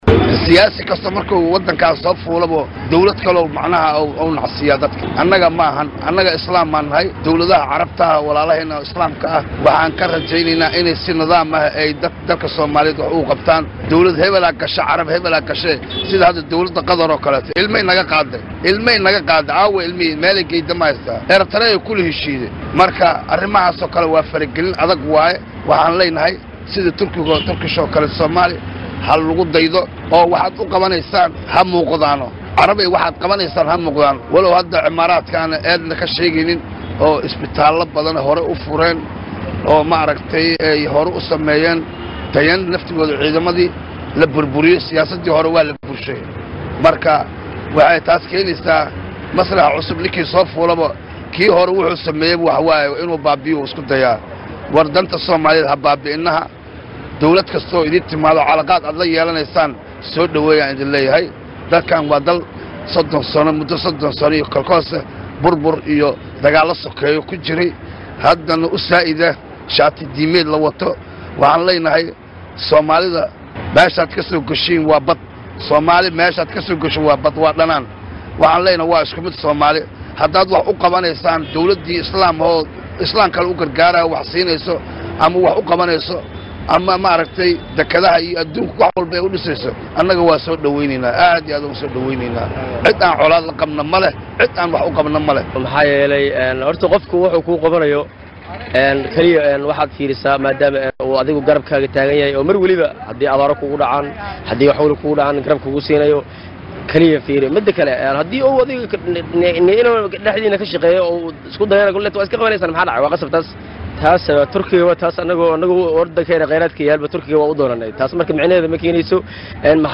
DHAGEYSO:Shacabka Muqdisho oo ka ka ra’yi dhiibtay heshiisyo ay wada gareen Soomaaliya iyo Imaaraadka
Heshiisyada ay Soomaaliya iyo Imaaraadka wada gaareen ayaa ku saabsanaa hagaajinta xiriirka iyo iskaashiga labada dal, fududeynta dhaqdhaqaaqa ganacsiga, isu socdka muwaadiniinta iyo bixinta dal ku galka. Haddaba, shacabka magaladda Muqdisho ayaa ku kala aragti duwanaaday heshiisyada ay kala saxiixdeen Soomaaliya iyo Imaraadka Carabta